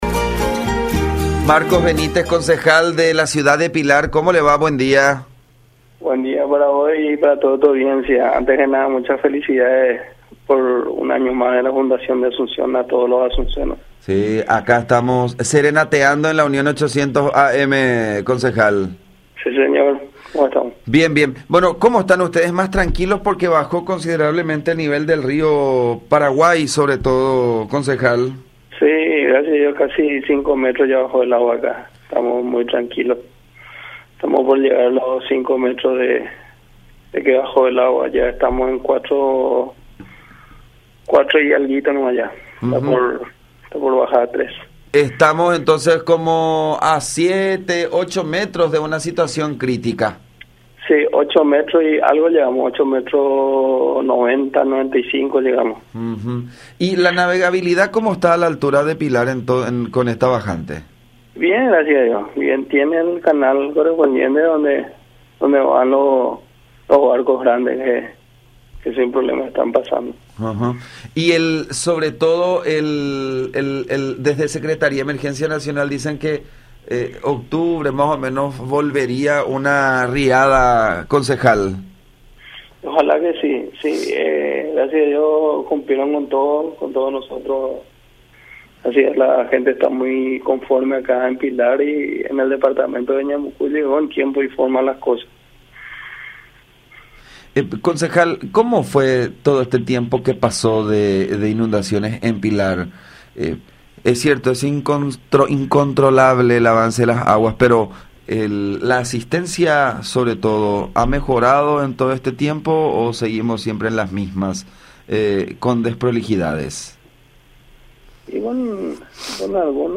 Así lo informó Marcos Benítez, concejal de Pilar, en contacto con La Unión, quien aseveró que el descenso de las aguas se aceleró con el uso de las motobombas.
05-Marcos-Benítez-Concejal-de-Pilar.mp3